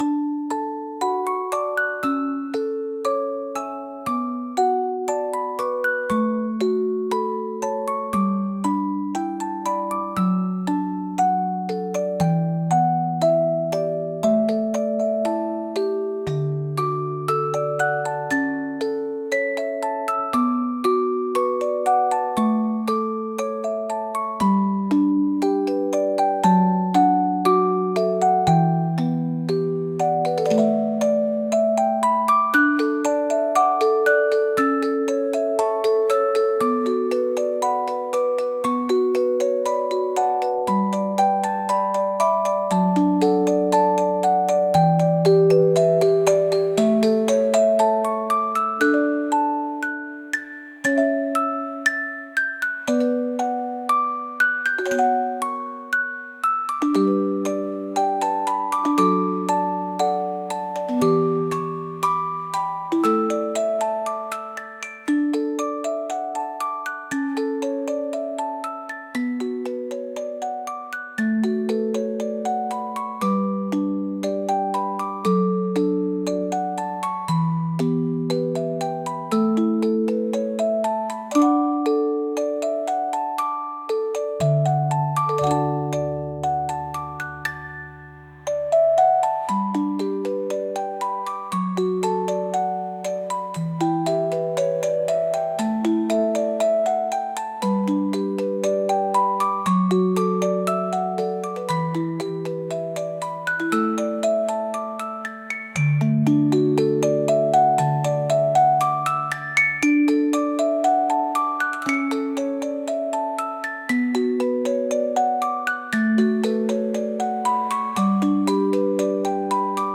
眠れない時にきくようなやさしいオルゴール曲です。